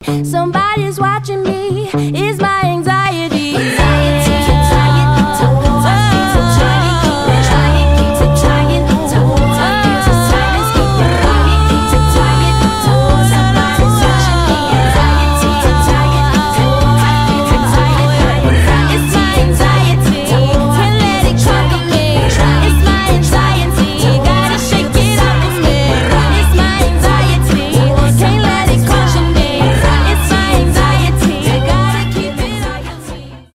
Рэп рингтоны
power pop
хип-хоп